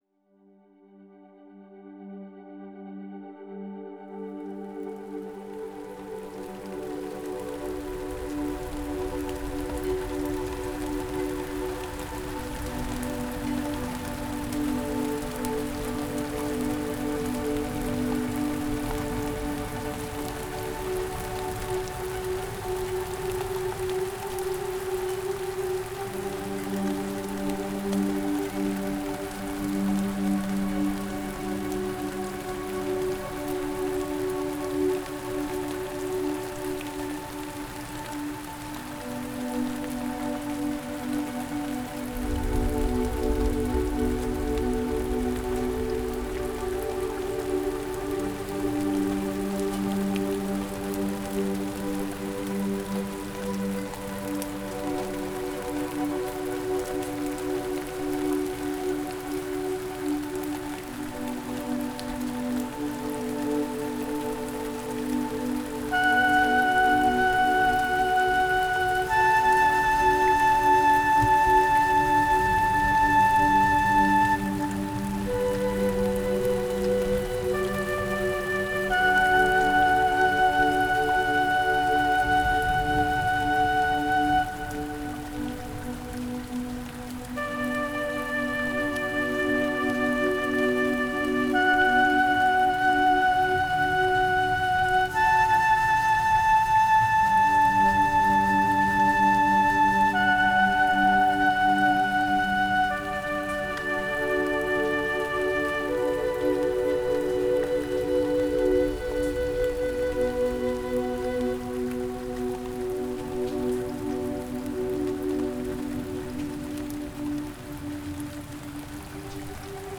Genre: New Age, Ambient, Relax.